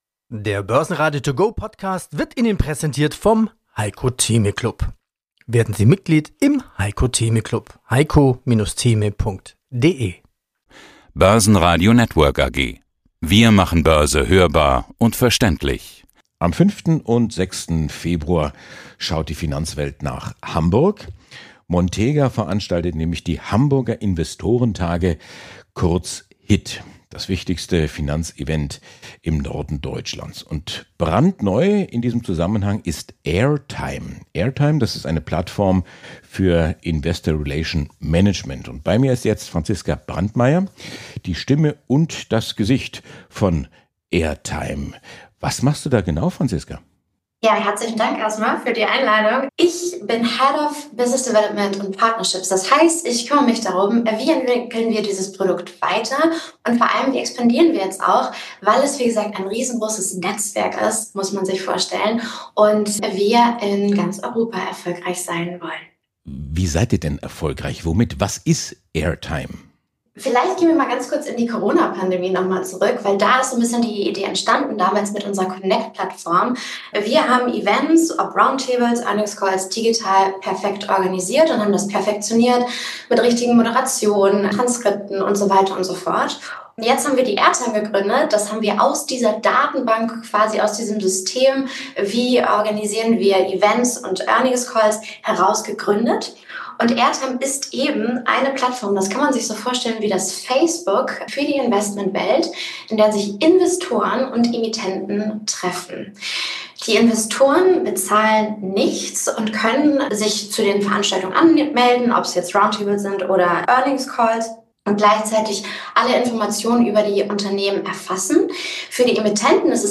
Alles rund um die Börse. Die Börse zum hören: mit Vorstandsinterviews, Expertenmeinungen und Marktberichten.